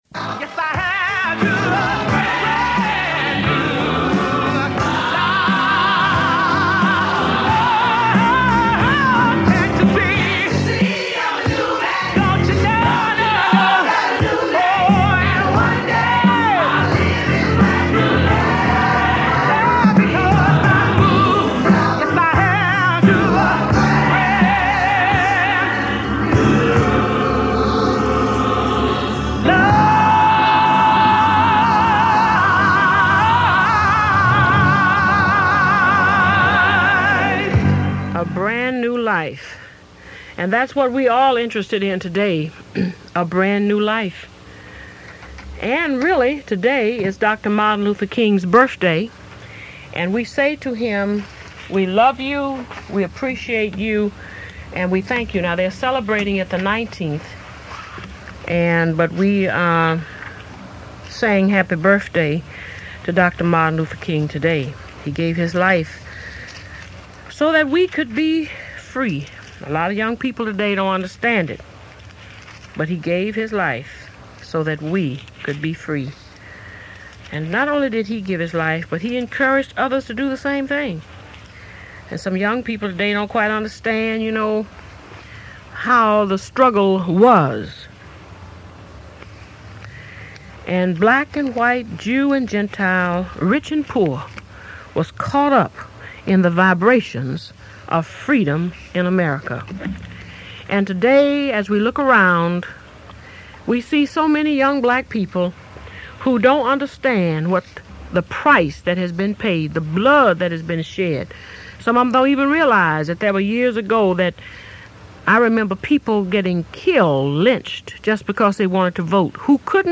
If this WQBH radio segment you will find inspiring, this is what she did best.